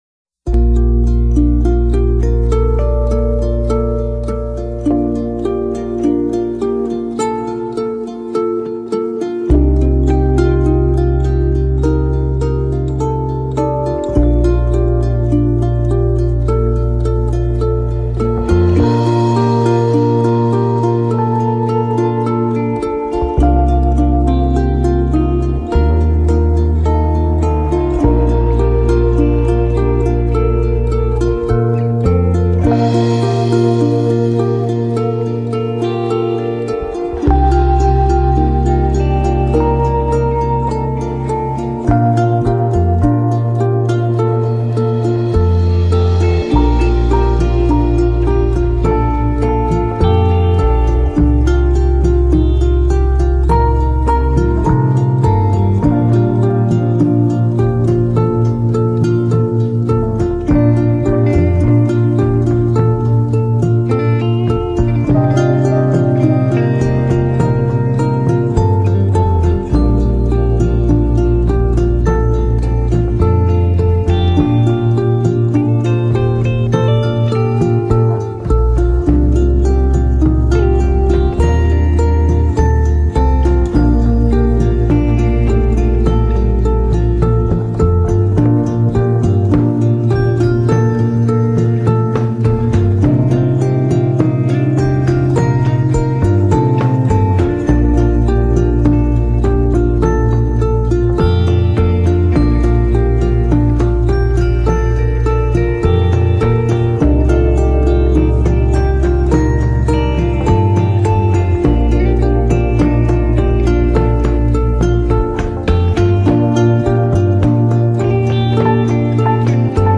Instrumental CS